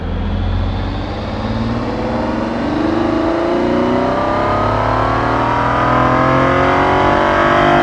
w12_01_accel.wav